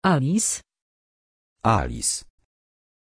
Pronunția numelui Alice
pronunciation-alice-pl.mp3